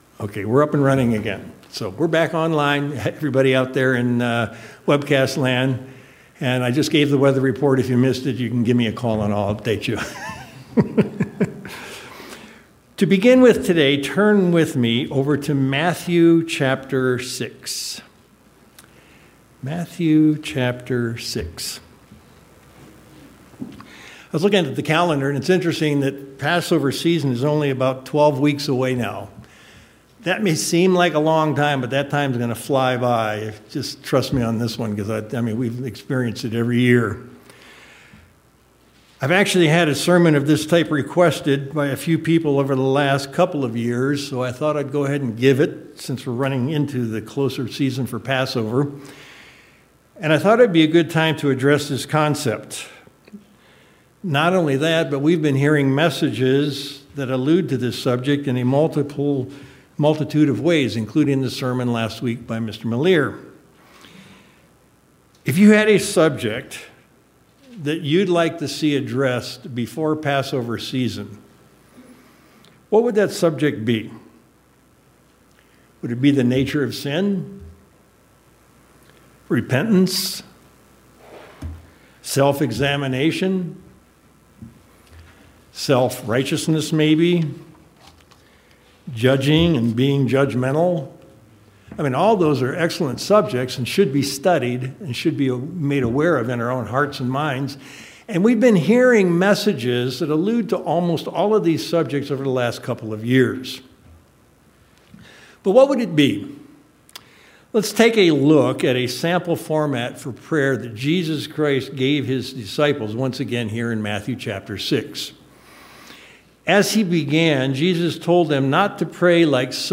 Given in Tucson, AZ El Paso, TX